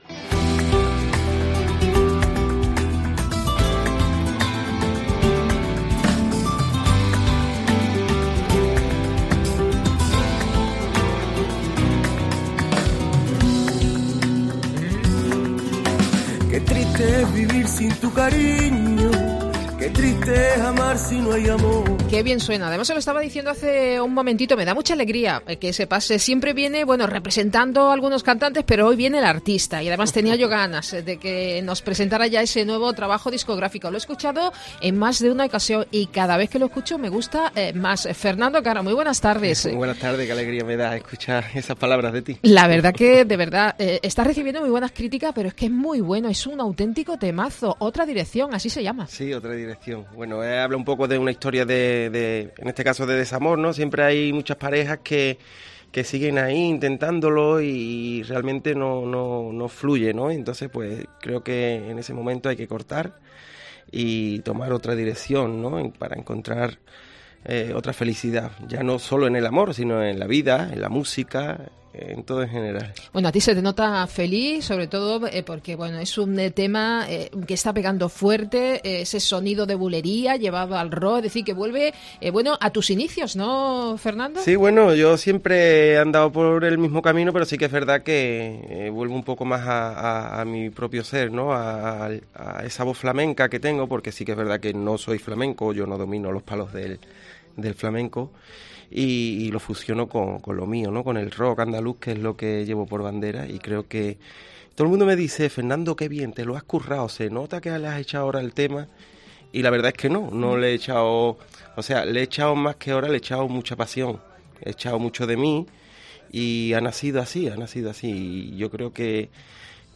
Una bujería llevada al Rock andaluz de forma magistral y que está consiguiendo maravillosas críticas del género.